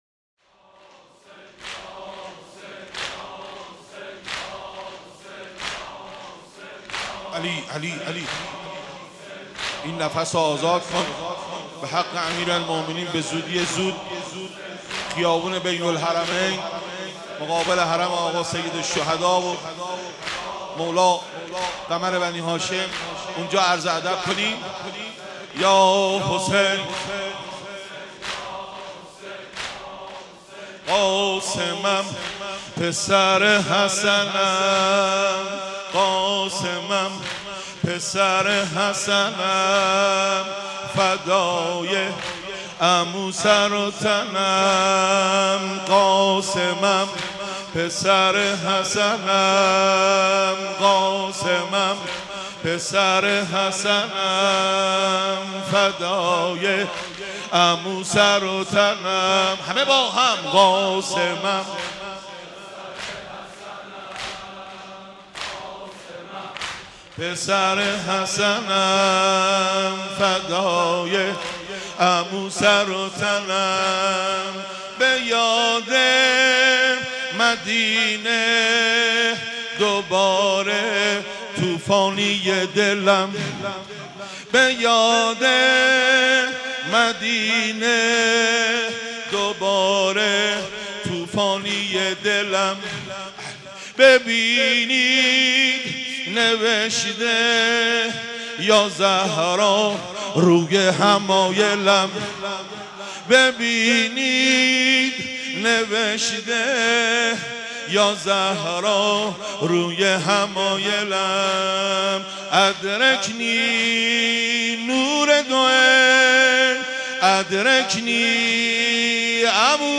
صوت/حاج محمود کریمی؛ شب ششم محرم 96
مداحی شور
مداحی واحد زمینه روضه